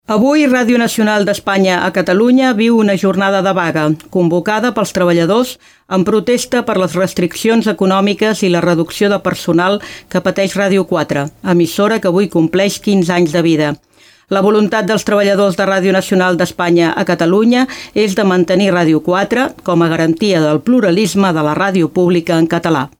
Lectura del comunicat de vaga dels treballadors de RNE a Catalunya en protesta de les reduccions de personal que pateix Ràdio 4 i en contra del seu possible tancament
Informatiu
FM